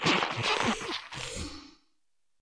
Cri de Filentrappe dans Pokémon Écarlate et Violet.